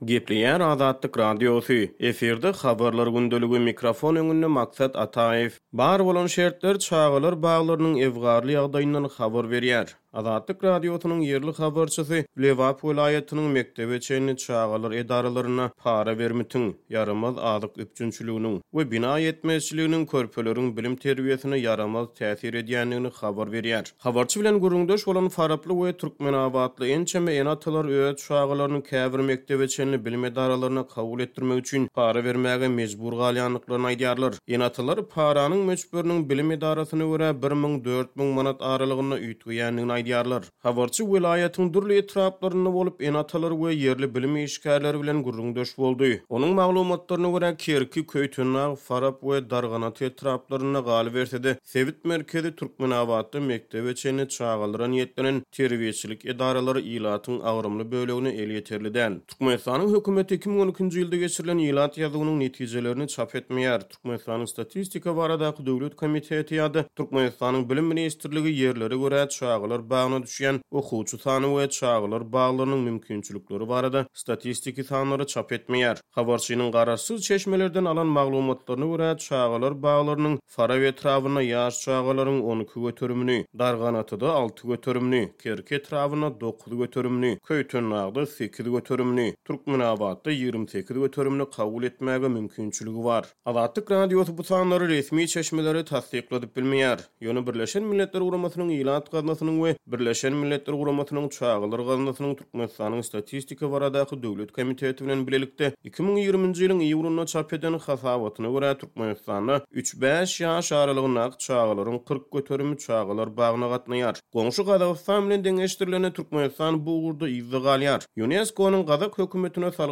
Azatlyk Radiosynyň ýerli habarçysy Lebap welaýatynyň mekdebe çenli çagalar edaralarynda para-bermitiň, ýaramaz azyk üpjünçiliginiň we bina ýetmezçiliginiň körpeleriň bilim-terbiýesine ýaramaz täsir edýändigini habar berýär.